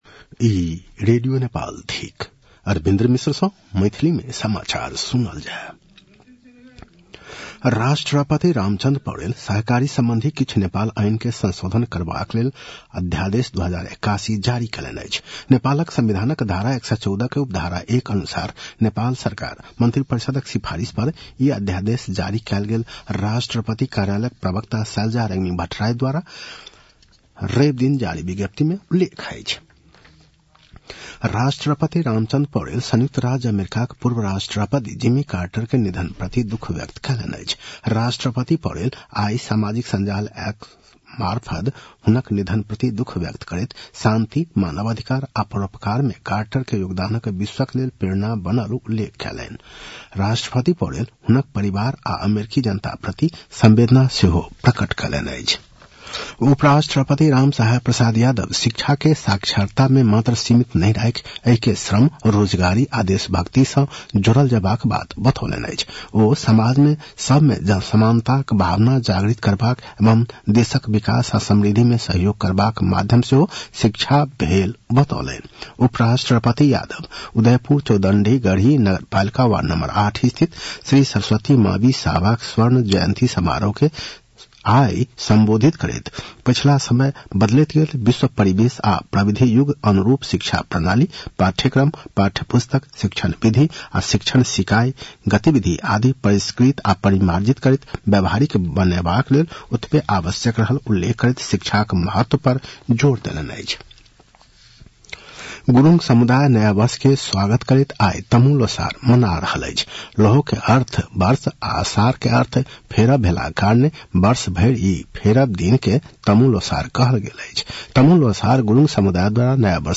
मैथिली भाषामा समाचार : १६ पुष , २०८१